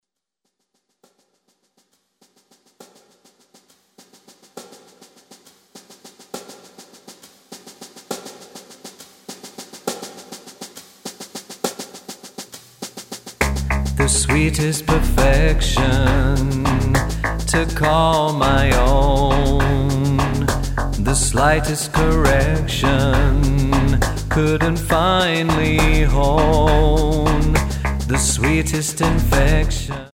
Tonart:C#m Multifile (kein Sofortdownload.
Die besten Playbacks Instrumentals und Karaoke Versionen .